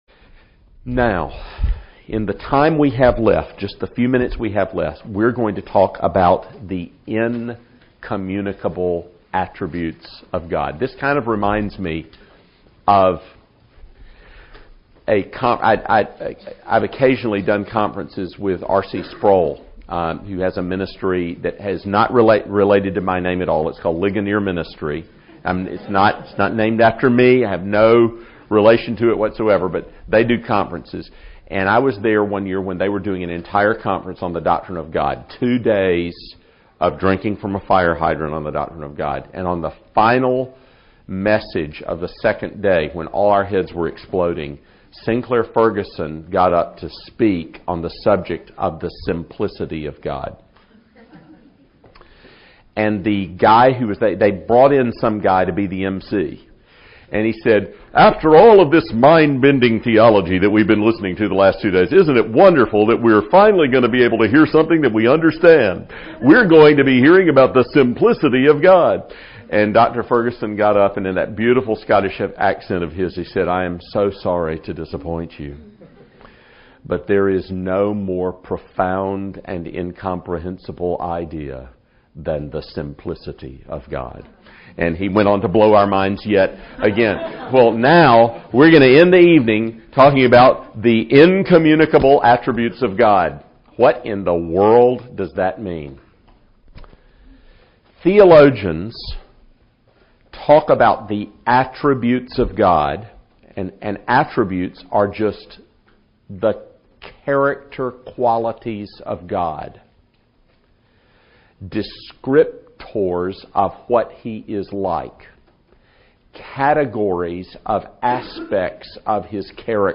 Lecture 3: Theology Proper: The Doctrine of God -The Incommunicable Attributes of God